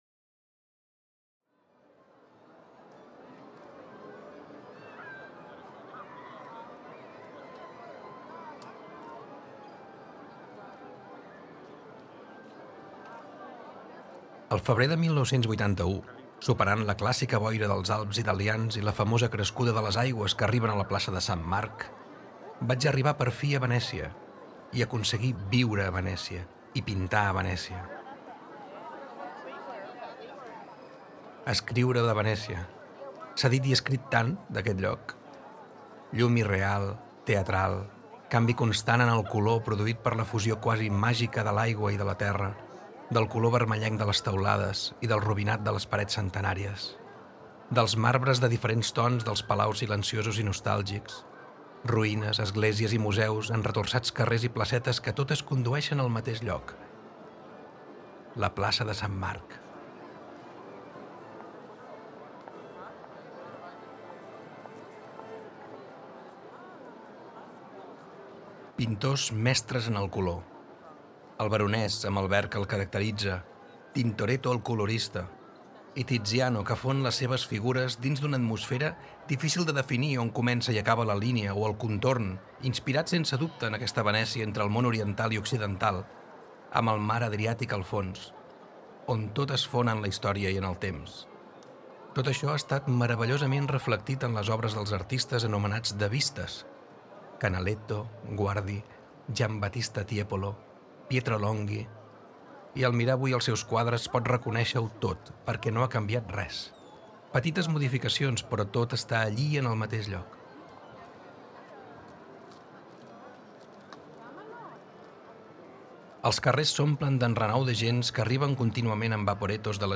We have recreated through voice and sound three fragments of letters that Joan Abelló wrote during his travels and that transport us to that place and at that moment.